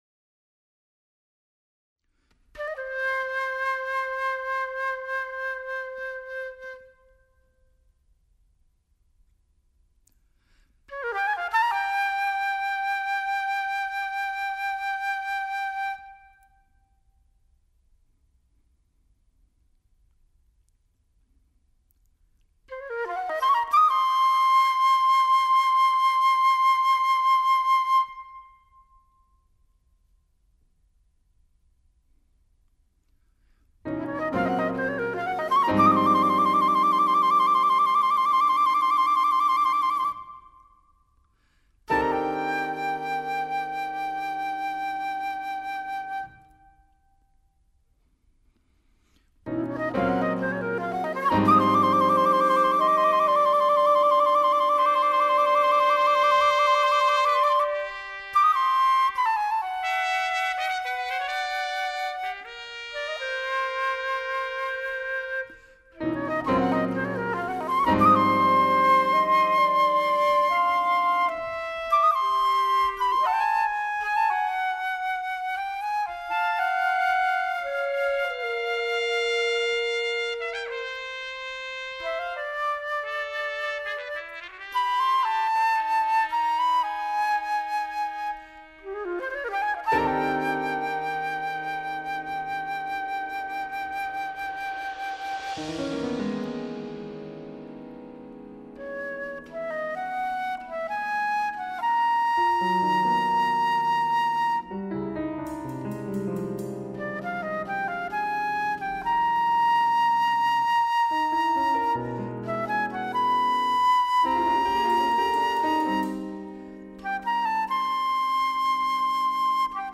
solo yodeling
Weni Geld's gnue hätt. Jodellied.